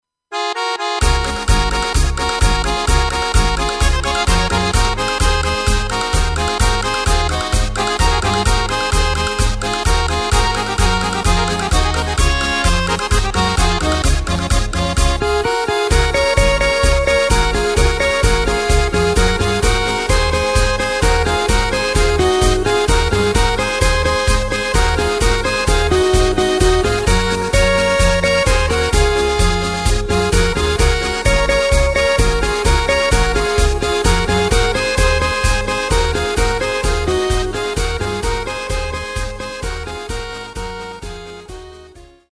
Takt:          2/4
Tempo:         129.00
Tonart:            Db